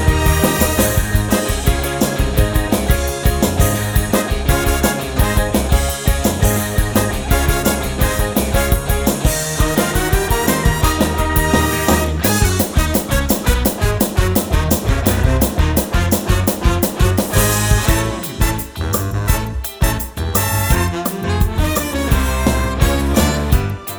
no Backing Vocals Soundtracks 5:24 Buy £1.50